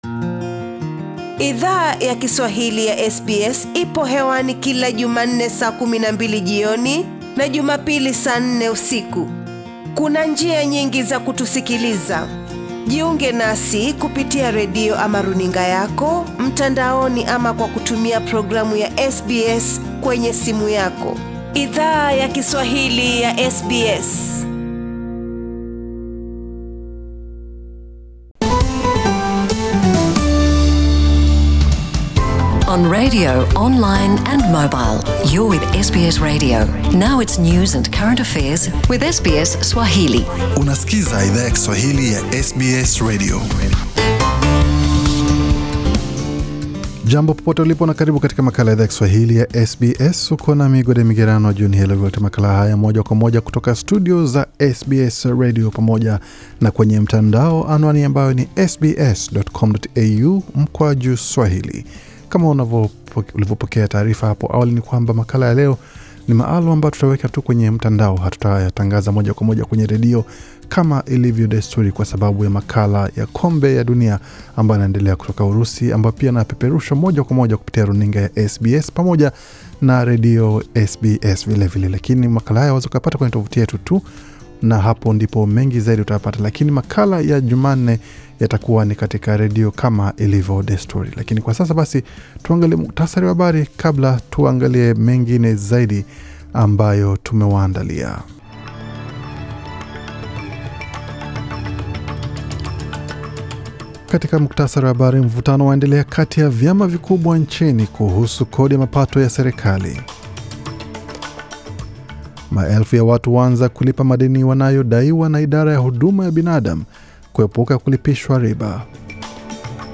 Taarifa ya habari ya Jumapili 24Juni2018